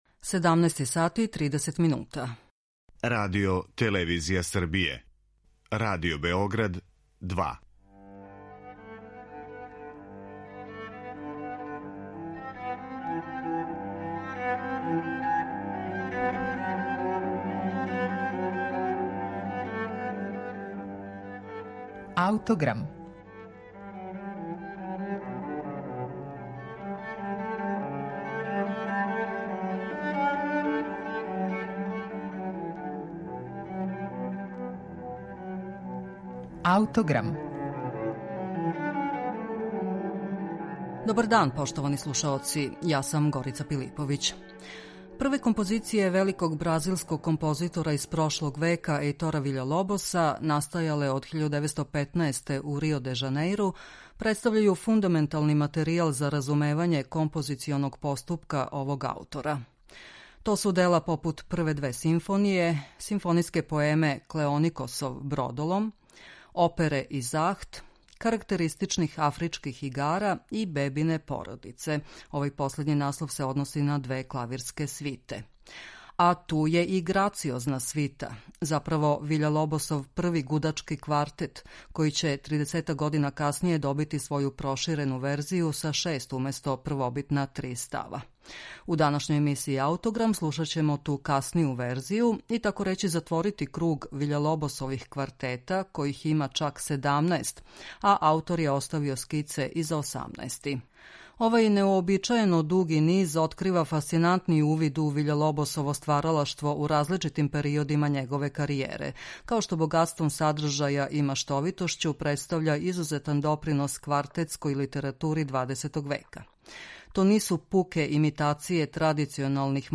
У данашњој емисији слушаћемо ту каснију верзију, и тако рећи затворити круг Ви